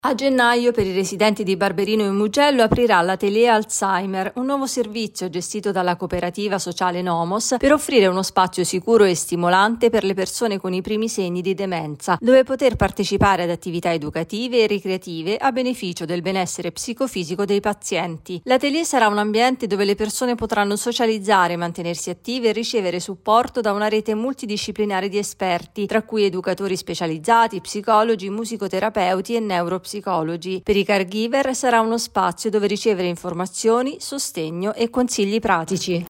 Disparità di accesso – Con i Bambini ha presentato il nuovo report su giovani e periferie. Ascoltiamo il presidente Marco Rossi Doria.